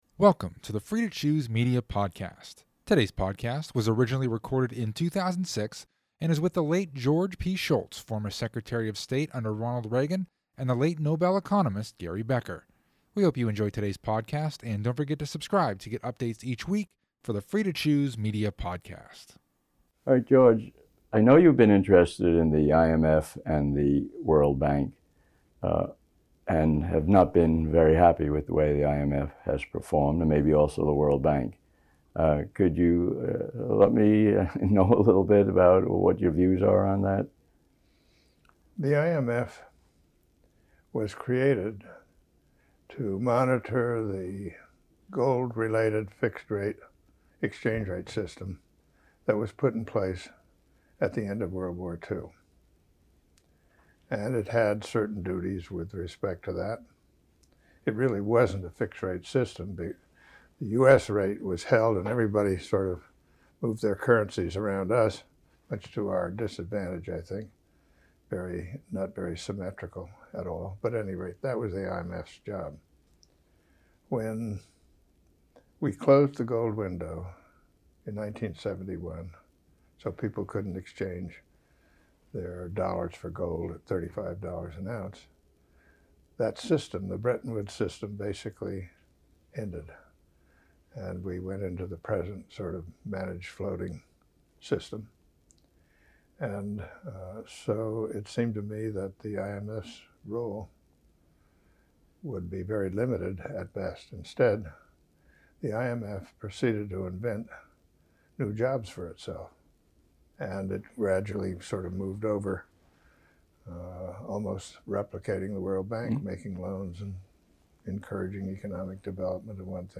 Episode 115 – A Conversation with George P. Shultz and Gary Becker